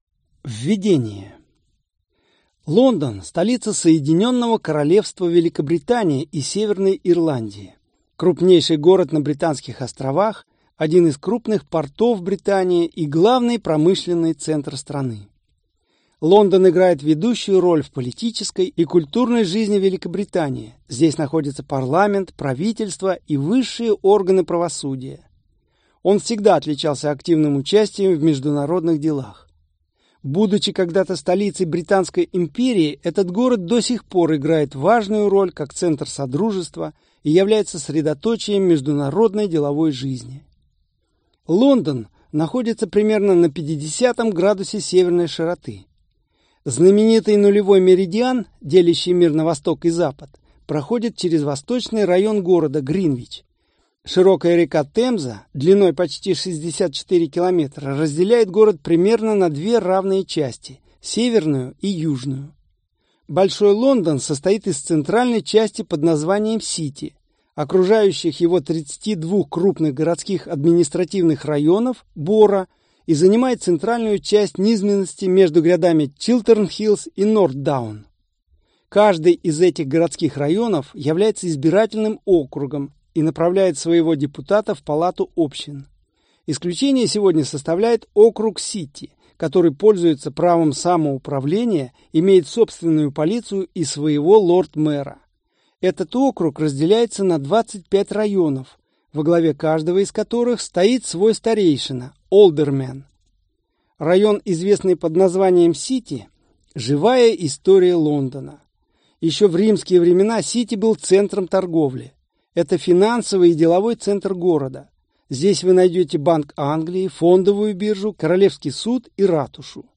Аудиокнига Лондон | Библиотека аудиокниг